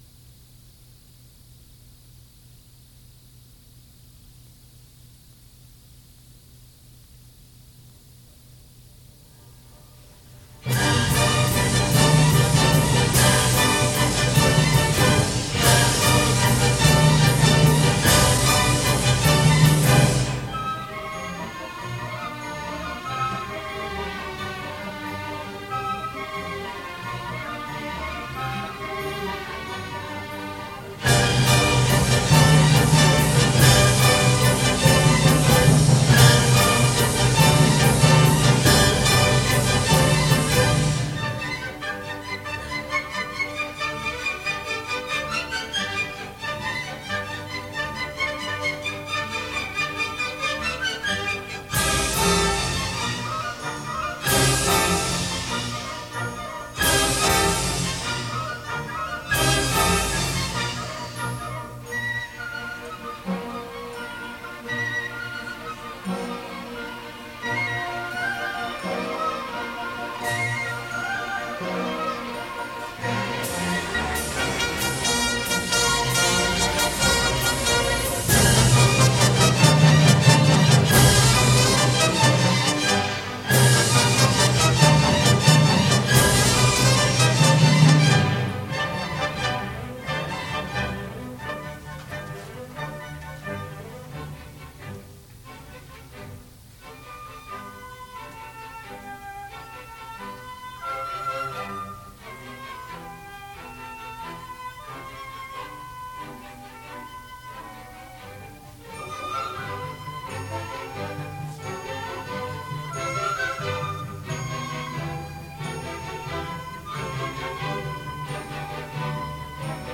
Senior High Orchestra
Senior High Chorus